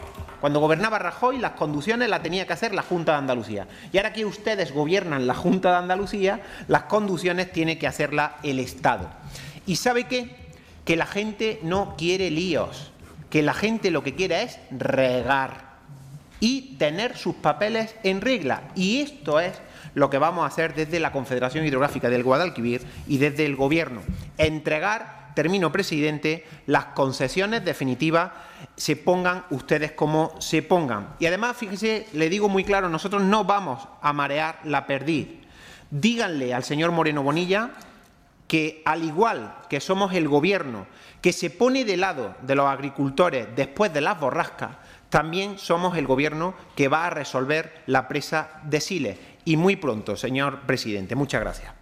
Latorre hizo estas manifestaciones en el Senado, donde el PP se ha negado a incluir una enmienda del PSOE para llegar a un acuerdo en la moción que había presentado la derecha sobre la Presa de Siles.